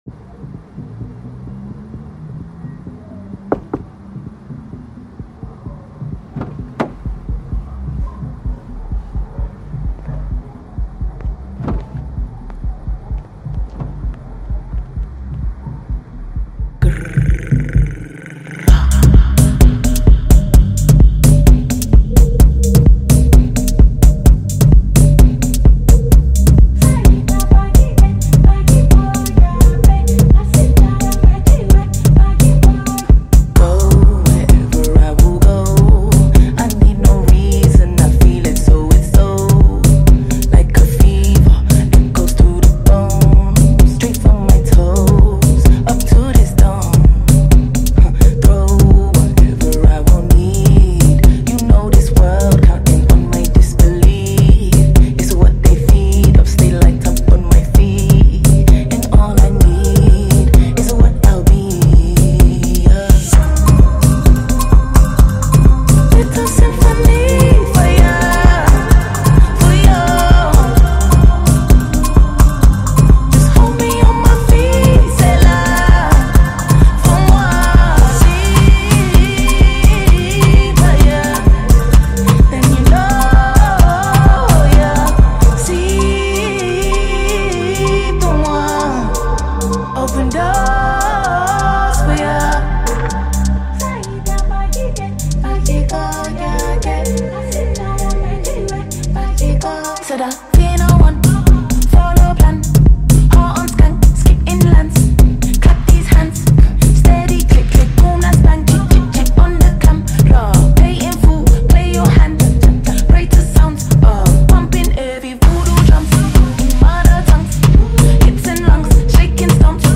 catchy melodies